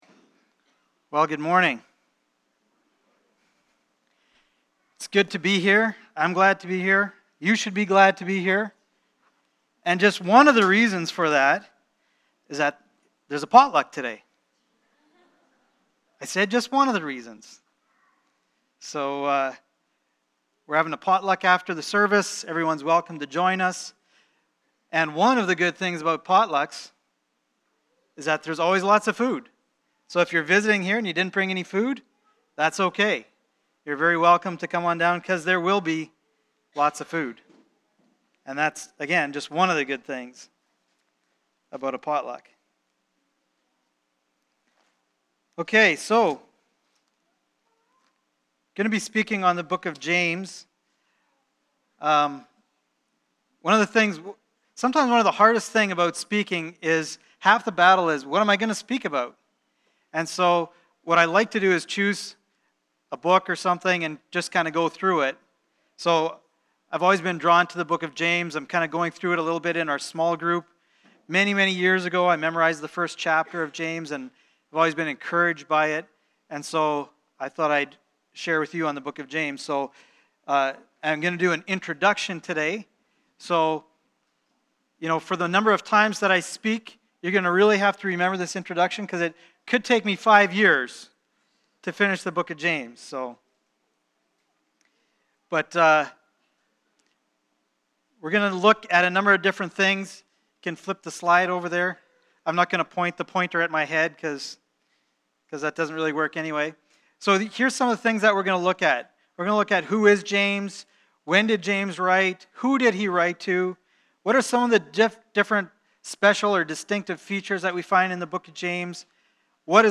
2 Peter 3:1-11 Service Type: Sunday Morning « 1 Thessalonians